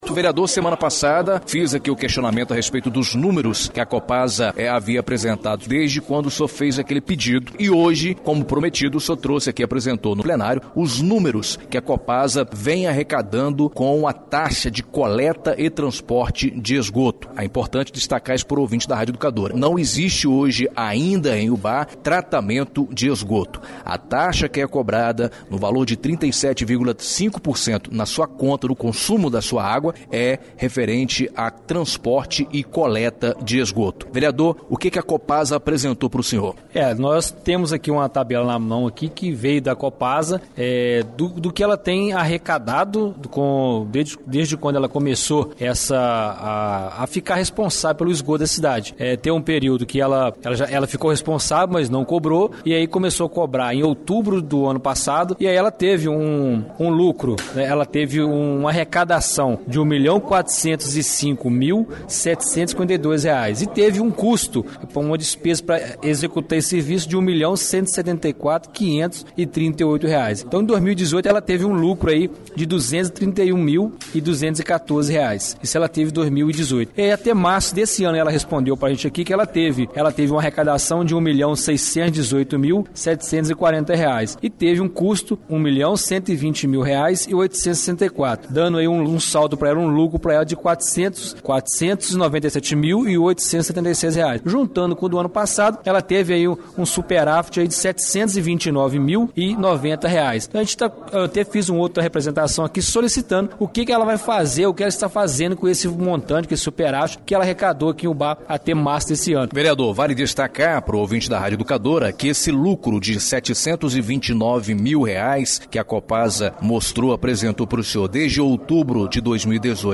vice – presidente da Câmara Municipal José Roberto Filgueiras